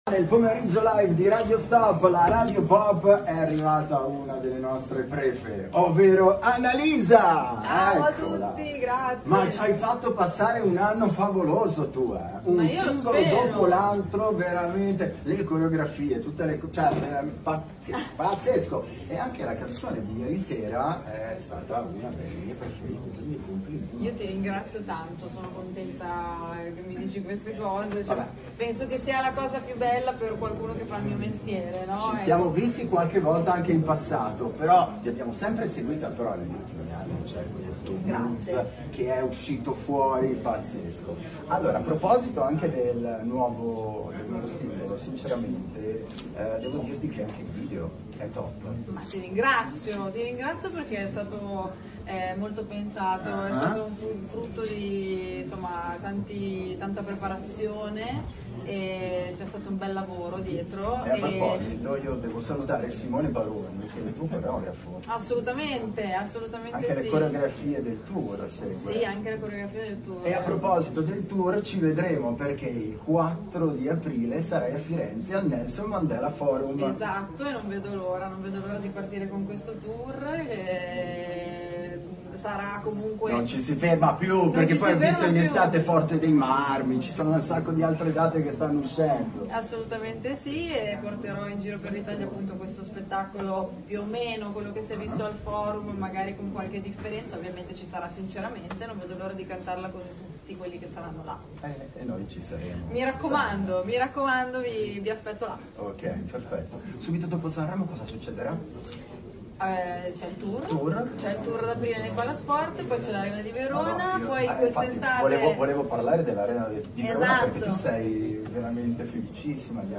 Festival di Sanremo con Radio Stop!
Radio Stop – Intervista a ANNALISA
Intervista-a-ANNALISA.mp3